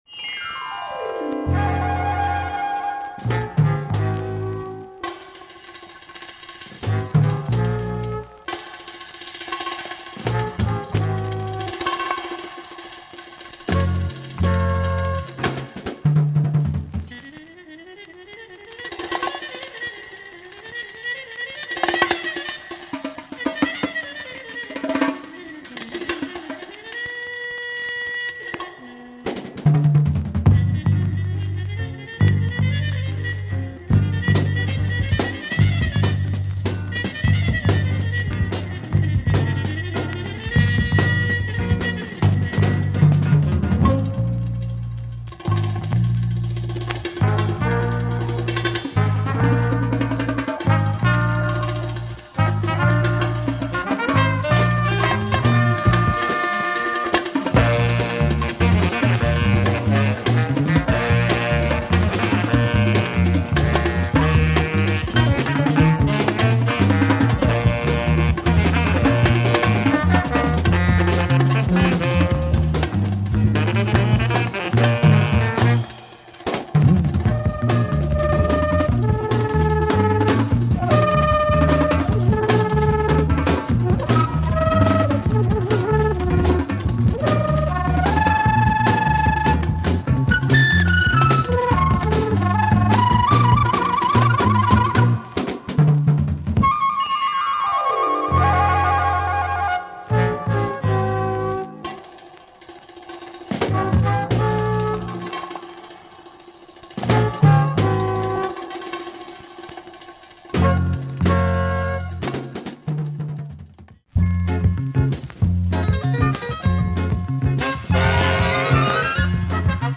filled with jerk & bongo beats
percussions and flute